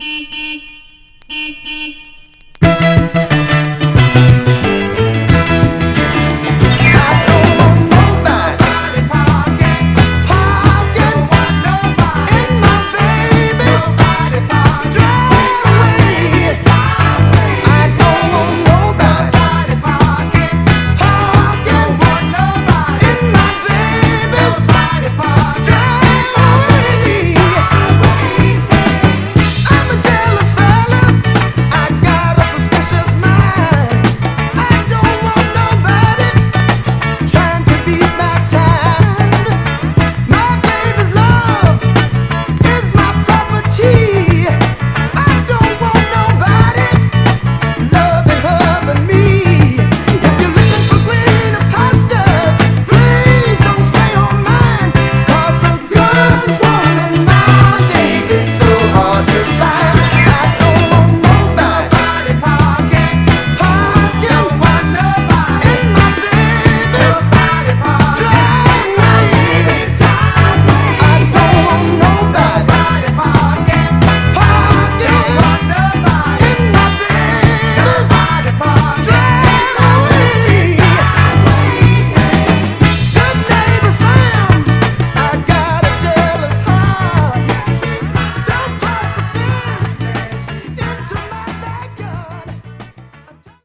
序盤等でチリつきますが、大きく目立つノイズは少なくプレイ問題ないかと。
※試聴音源は実際にお送りする商品から録音したものです※